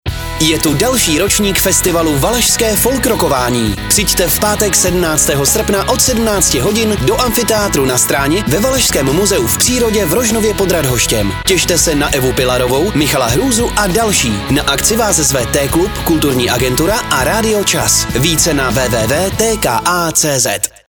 Spot Radio čas